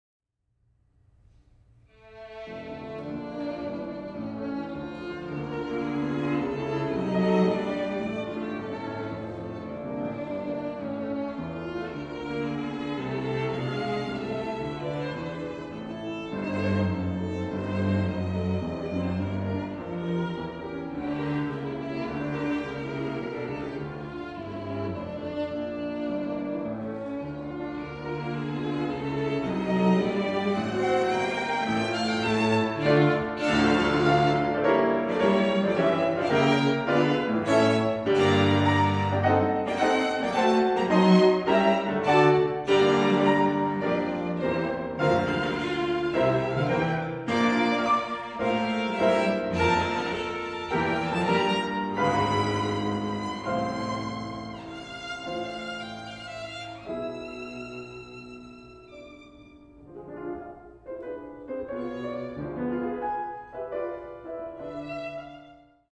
In the meantime, below are samples of live recordings.
(Lile Piano Trio)